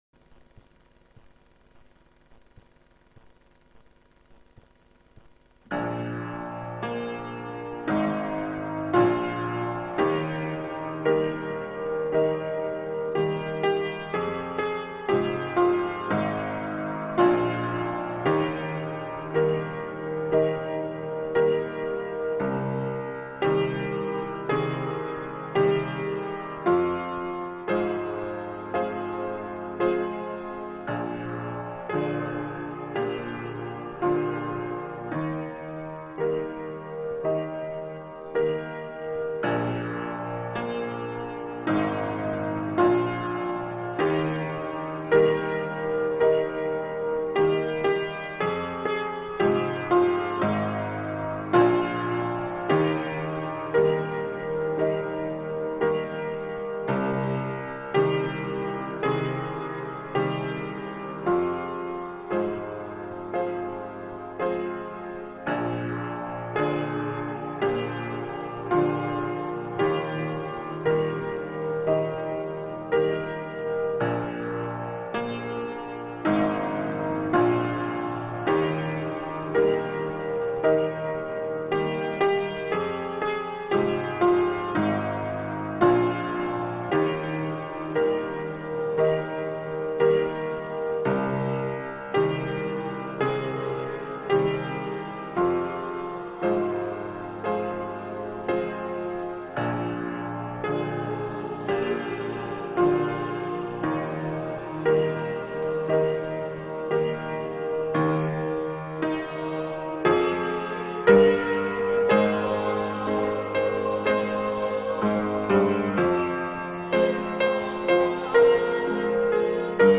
证道内容： 旧约圣经主要由希伯来文、少量由亚兰文写成；新约圣经由希腊文写成。